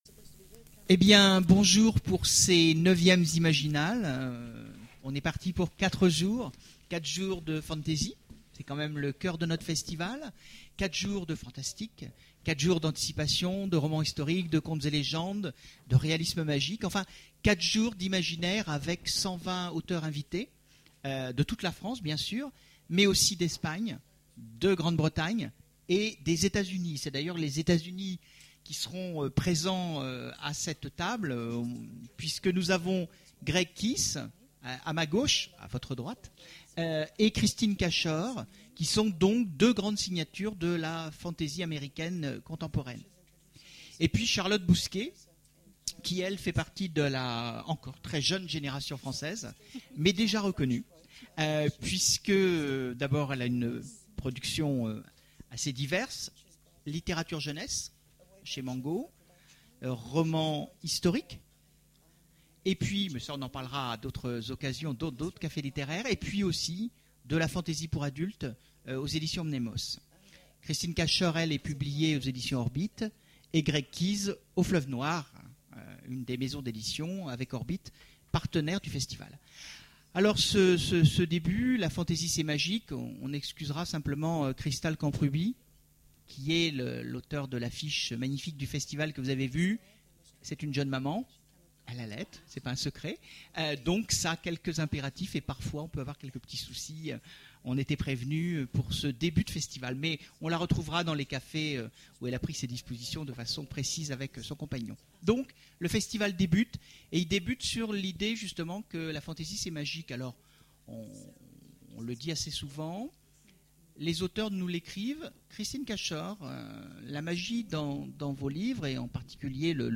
Voici l'enregistrement de la conférence La Fantasy... C'est rien que du bonheur ! aux Imaginales 2010